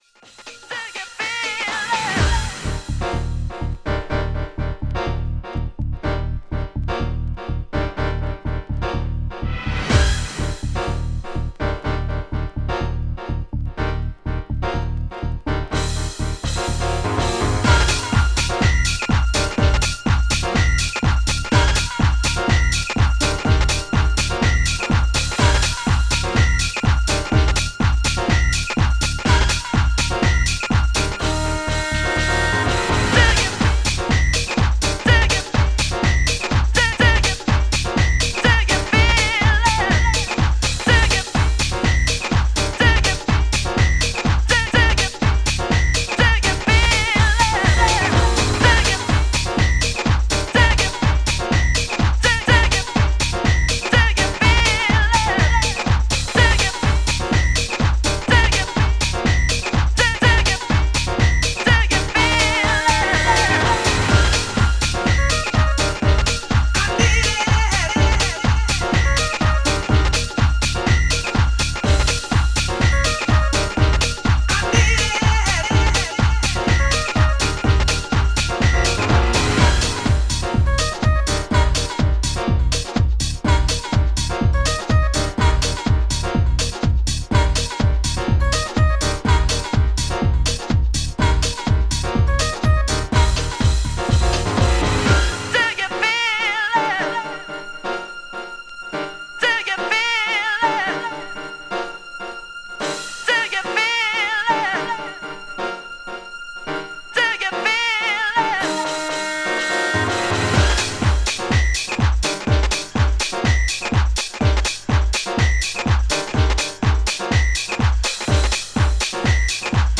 Mid-90s House / Tribal House 4trax!